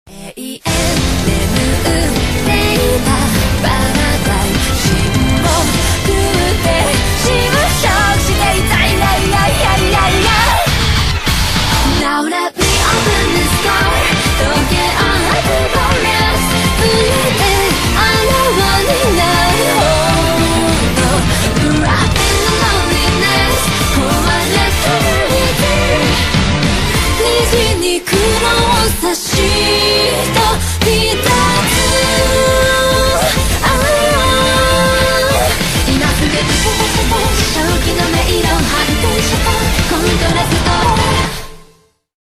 OST
K-Pop